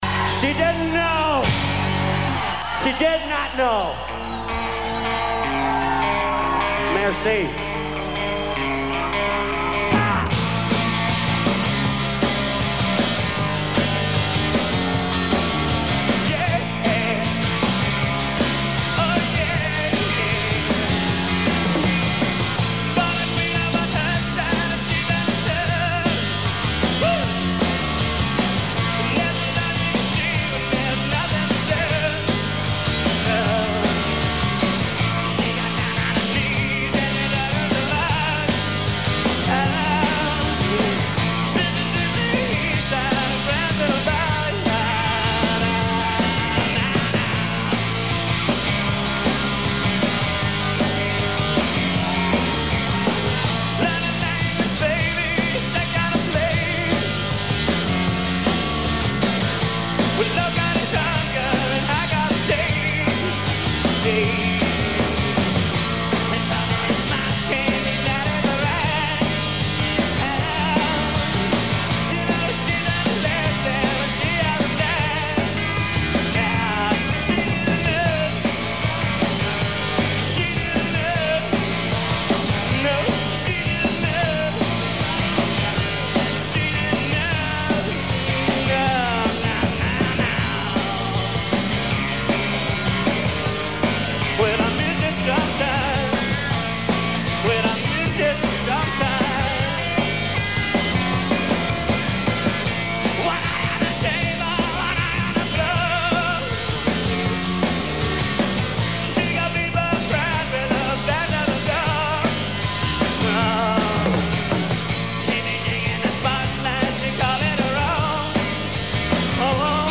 Tucson, AZ - Coyotes
Source: Audience